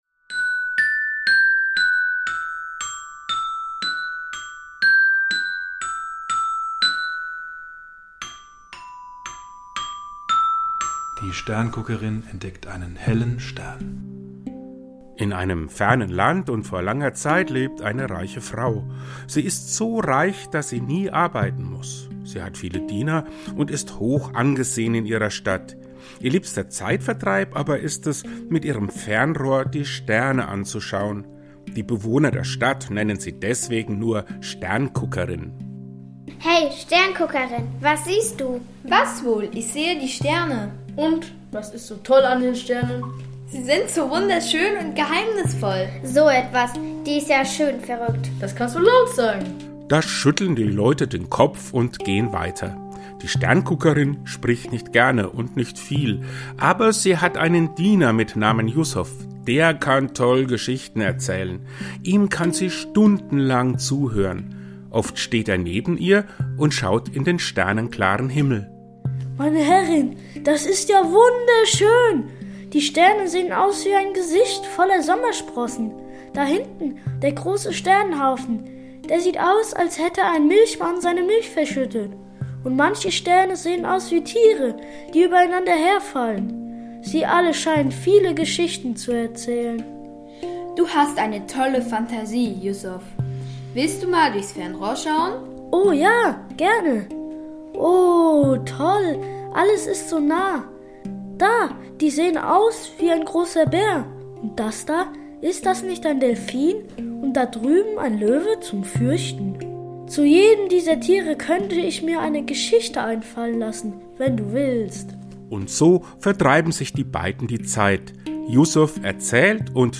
Ein Adventshörspiel